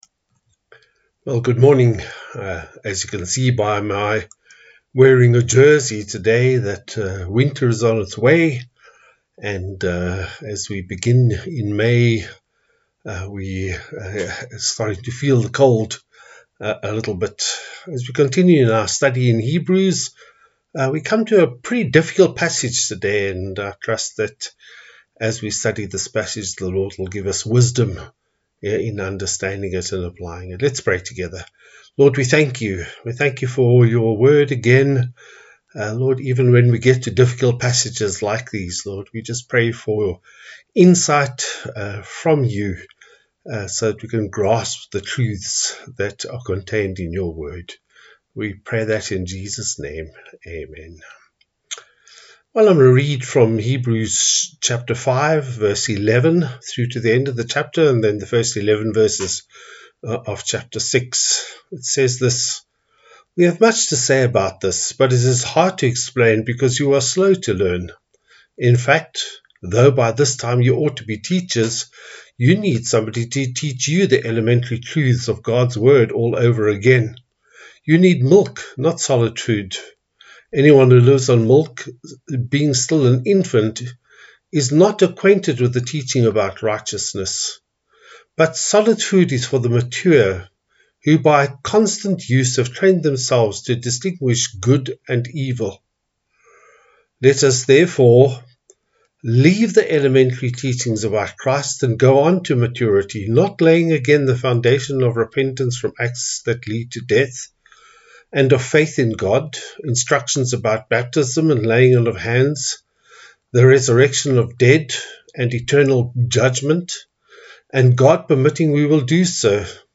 Passage: Hebrews 5:11-14, 6:1-12 Service Type: Sunday Service